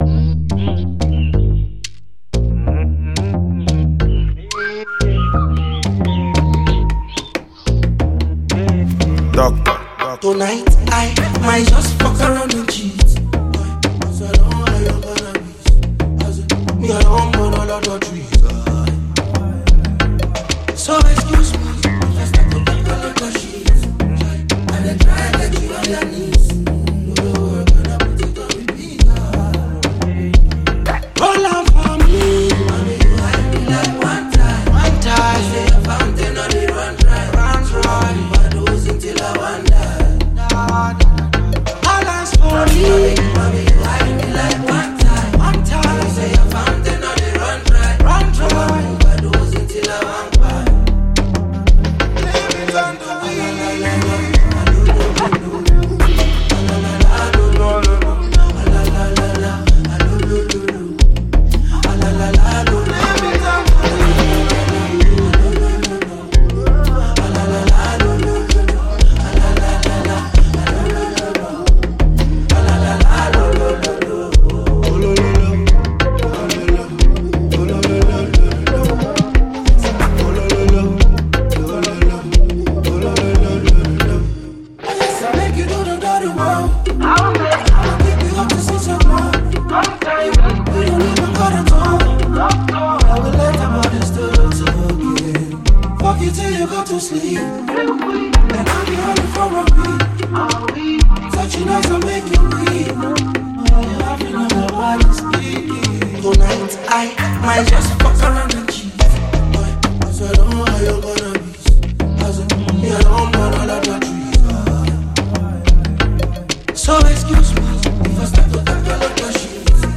Afrobeats